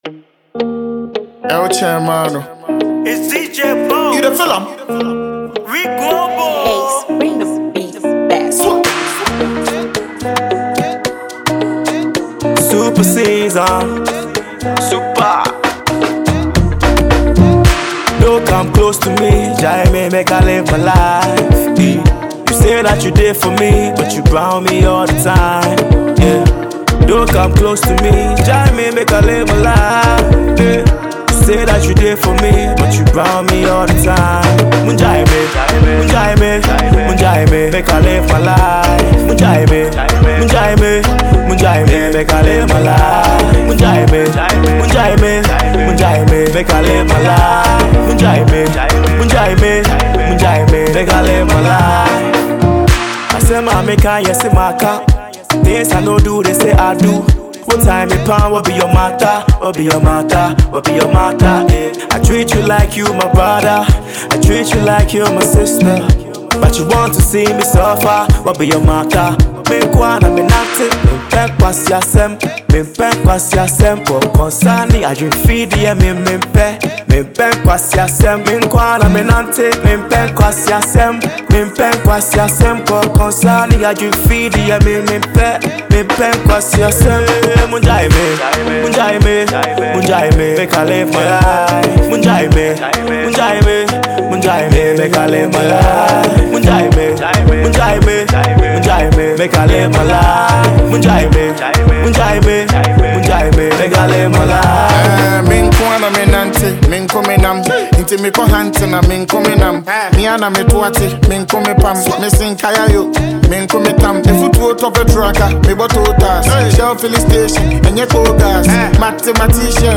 a clean, hard-hitting beat